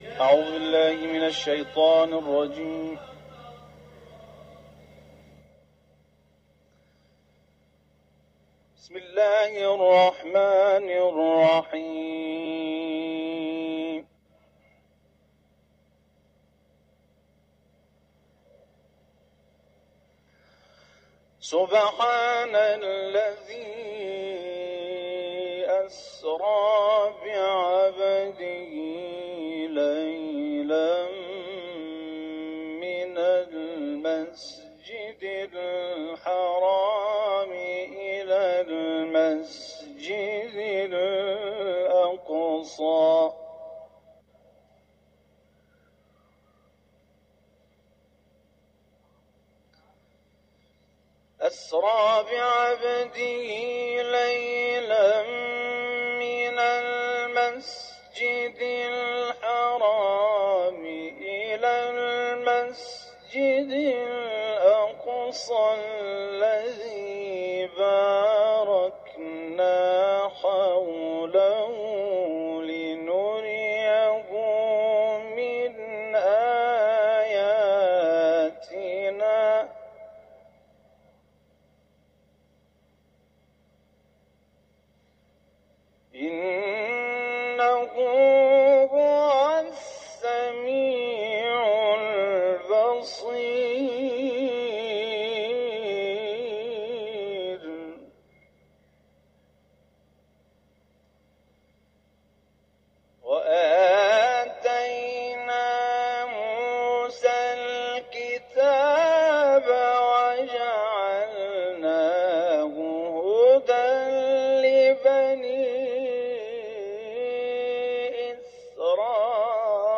تلاوت ، سوره اسرا ، حرم مطهر رضوی